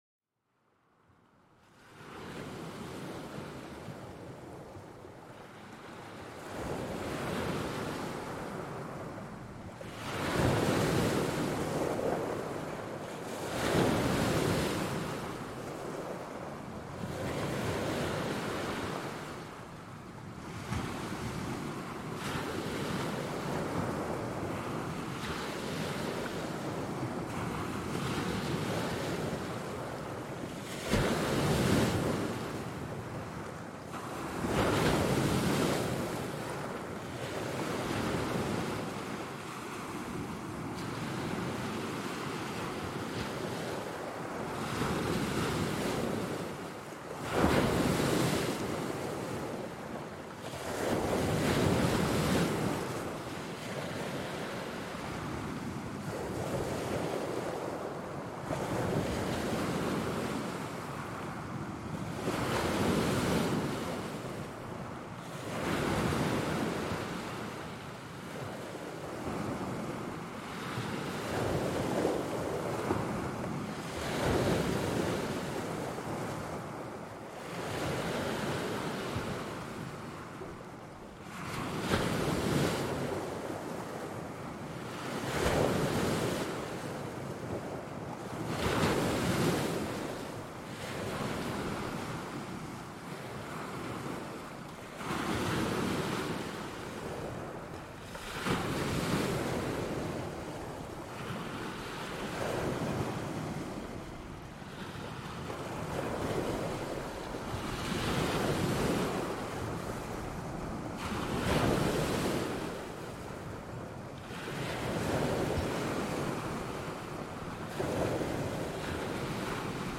Vagues apaisantes pour un sommeil profond
Le son doux des vagues vous enveloppe, vous aidant à lâcher prise et à plonger dans un sommeil réparateur.Écoutez les vagues qui s'écrasent doucement contre le rivage et laissez-vous emporter par leur rythme apaisant. Ce son vous aidera à réduire le stress et à vous relaxer complètement.